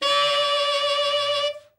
Index of /90_sSampleCDs/Giga Samples Collection/Sax/SAXOVERBLOWN
TENOR OB  20.wav